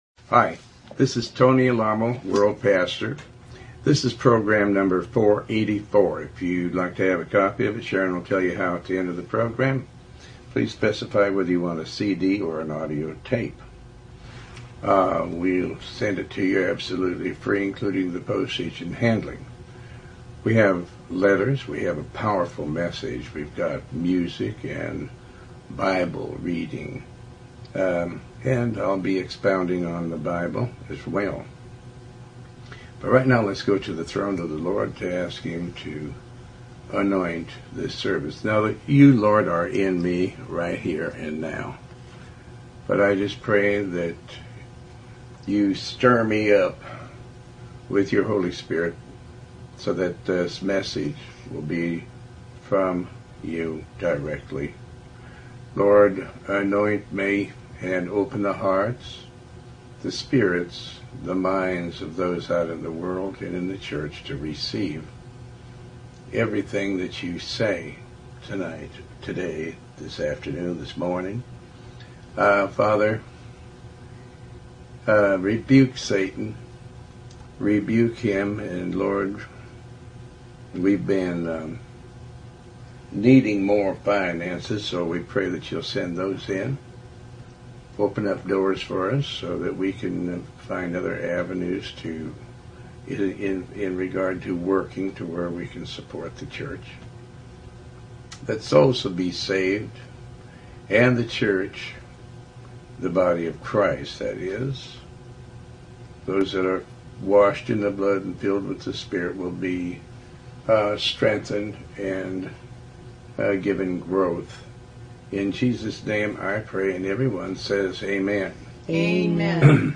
Talk Show Episode, Audio Podcast, Tony Alamo and Program 484 on , show guests , about Faith,Tony Alamo Christian Ministries,pastor tony alamo,Bible Study, categorized as Health & Lifestyle,History,Love & Relationships,Philosophy,Psychology,Christianity,Inspirational,Motivational,Society and Culture